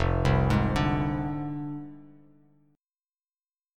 Listen to Fm6 strummed